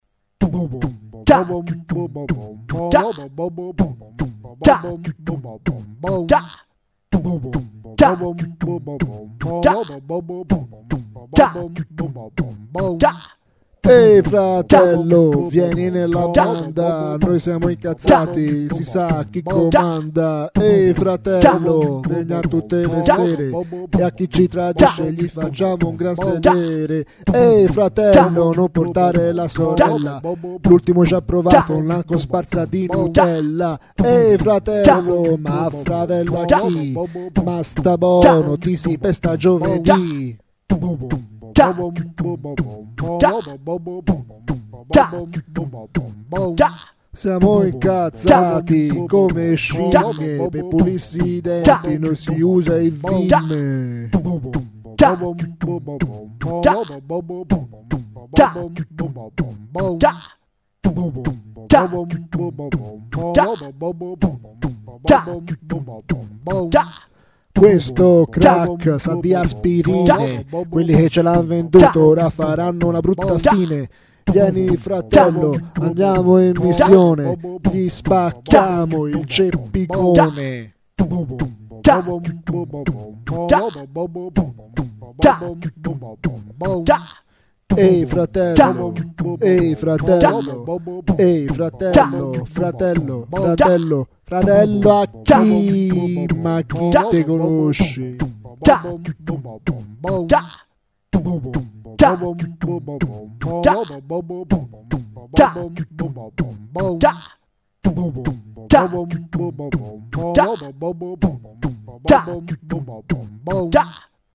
Sono passato di recente ad un programma di registrazione un po' piu' sofisticato, con loop e multitraccia; da una prova e' venuto fuori questo delirio di Rap con tutti gli strumenti fatti a bocca, siete avvisati!